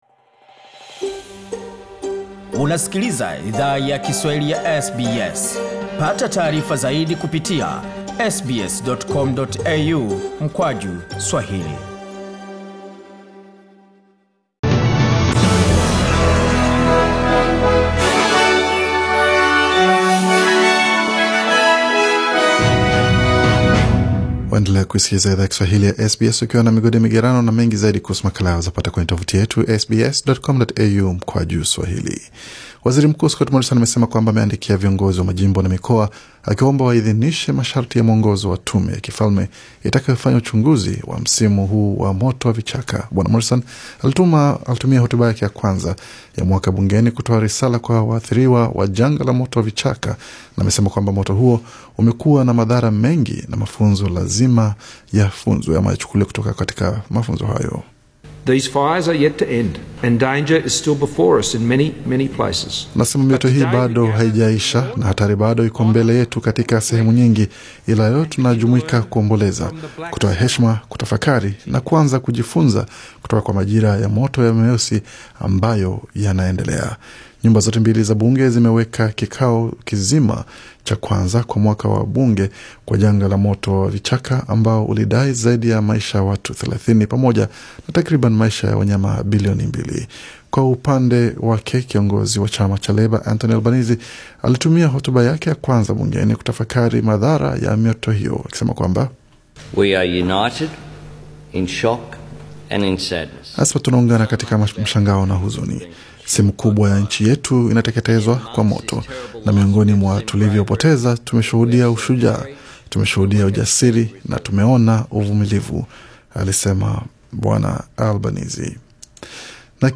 Taarifa za habari: Vyama vyakisiasa viwili nchini vyapata viongozi wapya